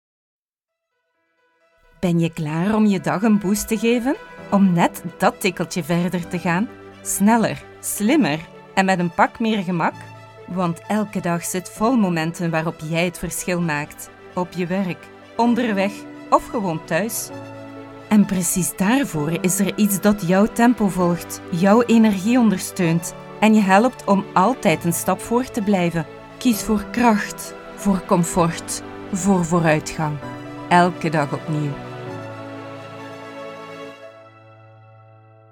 Vous pourrez compter sur un enregistrement impeccable de la voix néerlandaise flamande (belge), la voix française, la voix anglaise ou la voix espagnole grâce à l’équipement professionnel dont je dispose.
Démo Pub – Exemple voix off en Néerlandais (Flamand )